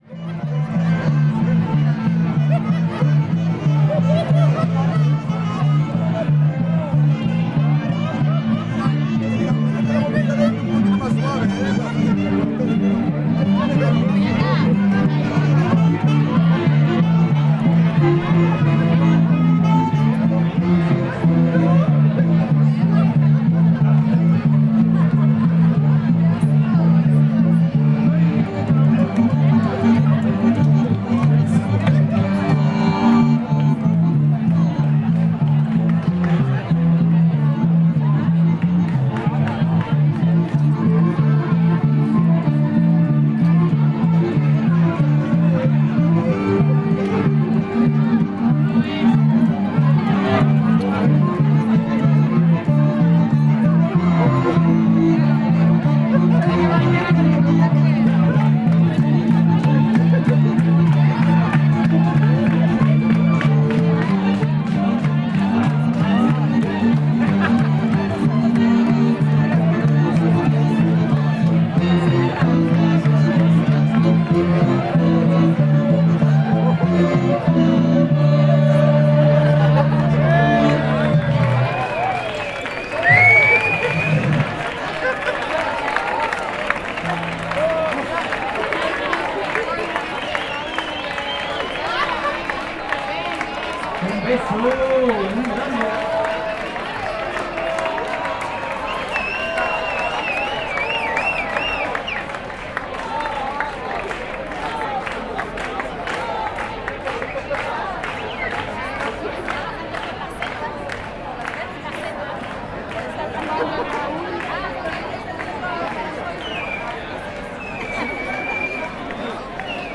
A protest about turning part of La Alameda in Seville into a car park, with music and chanting, May 2015.